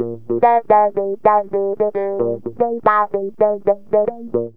GTR 18A#M110.wav